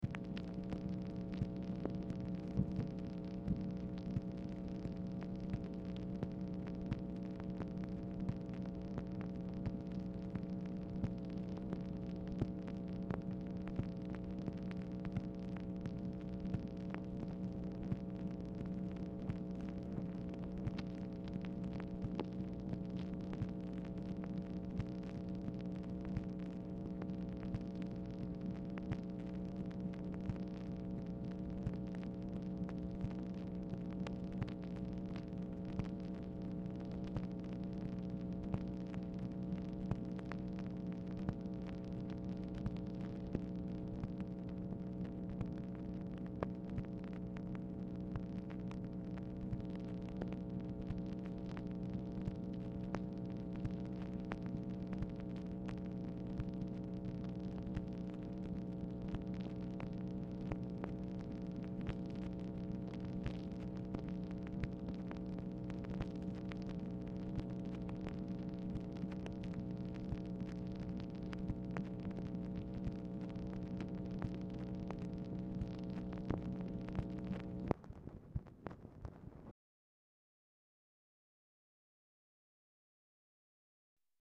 Telephone conversation # 5958, sound recording, MACHINE NOISE, 10/23/1964, time unknown | Discover LBJ
Format Dictation belt